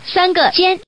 Index of /fujian_bm_yrc/update/3073/res/sfx/common_woman/